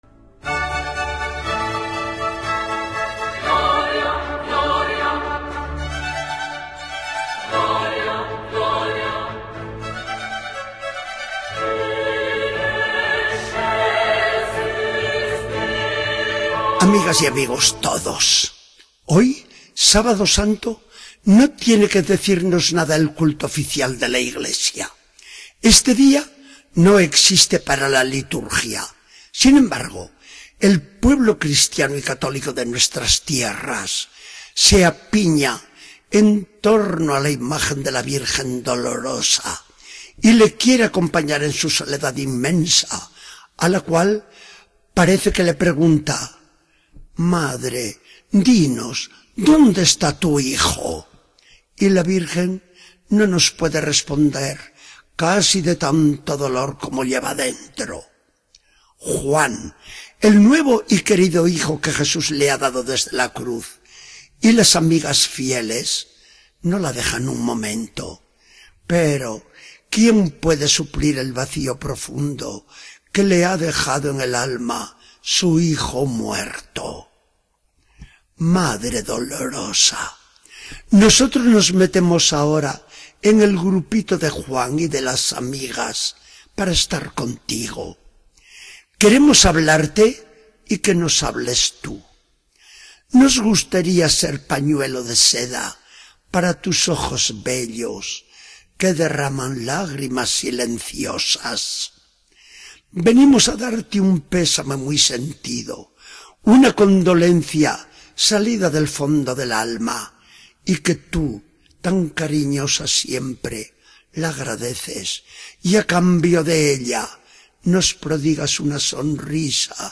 Charla del día 19 de abril de 2014.